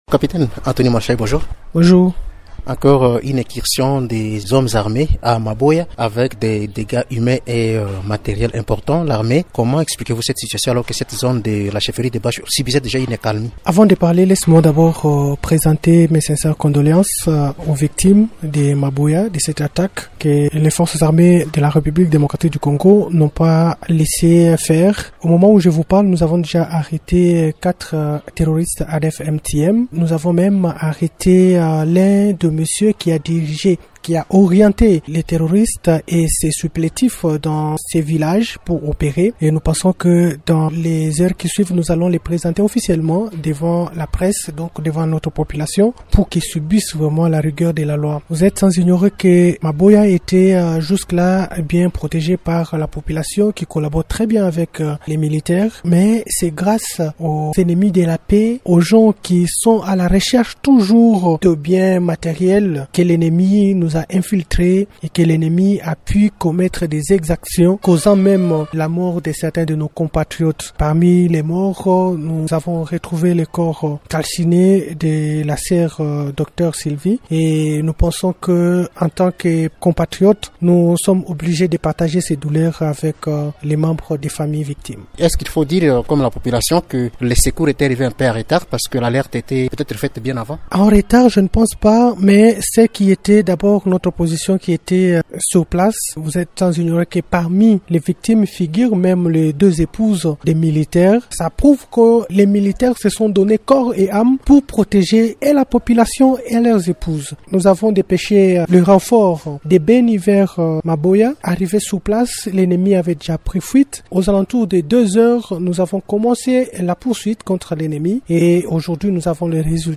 Invité de Radio Okapi